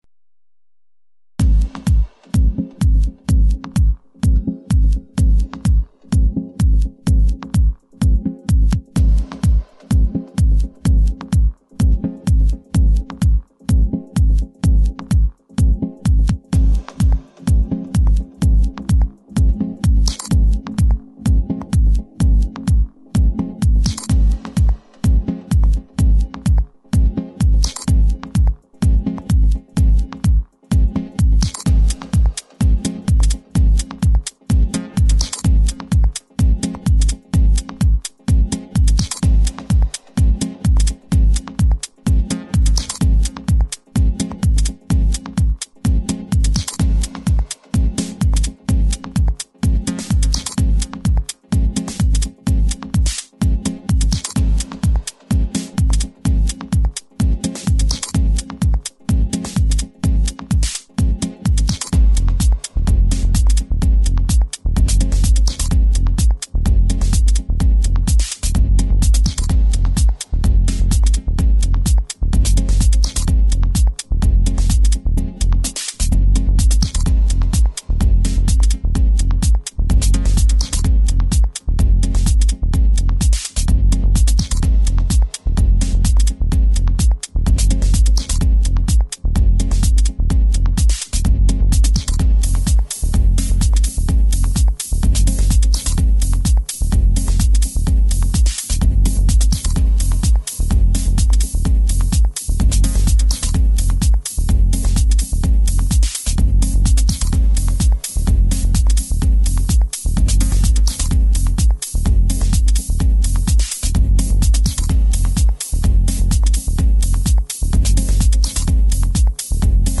Old School progressive house with a dark and sexy twist.
dj mix
tech house